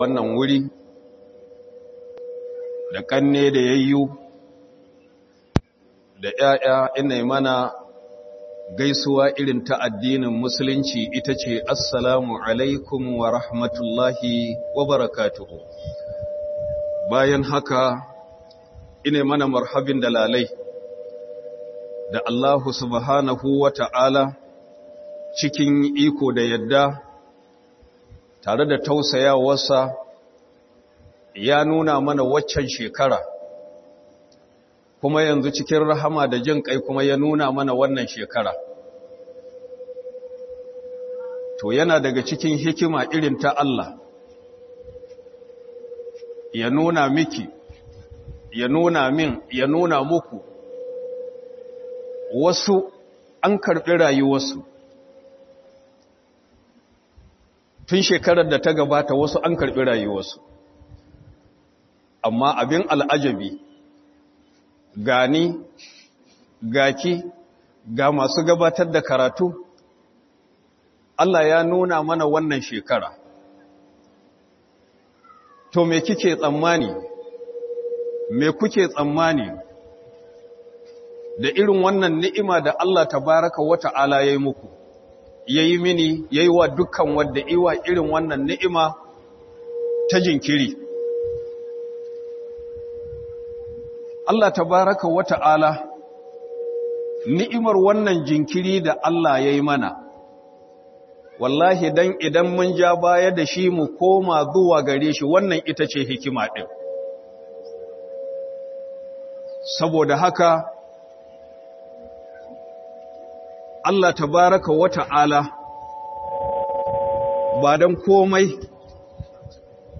Komawa Zuwa ga Allah Don Samun Mafita - Muhadara